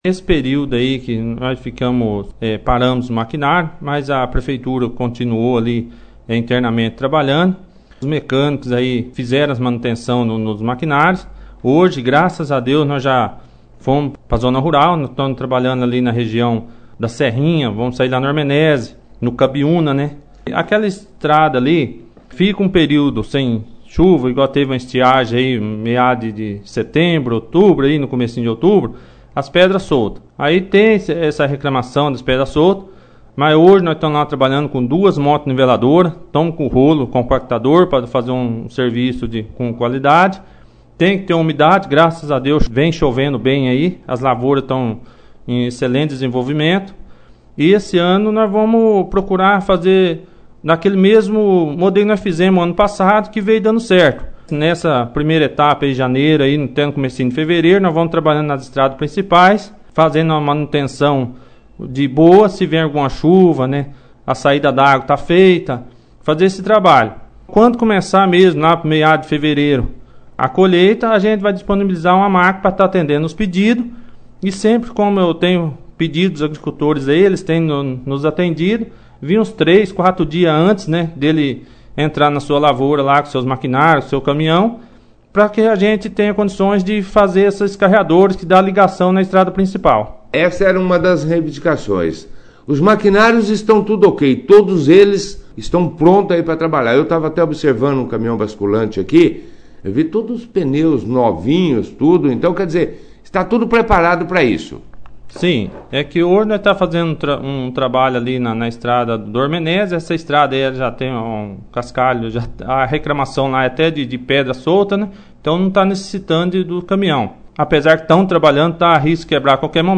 O Secretário da Agricultura e Pecuária de Bandeirantes, Renato Reis, (foto), participou da 2º edição do jornal Operação Cidade desta segunda-feira, 07/01/2020, falando sobre o trabalho desenvolvido e das dificuldades enfrentadas principalmente quanto a frota de maquinário do município que estavam em revisão, cascalha mento dentre outros assuntos relativos a secretaria.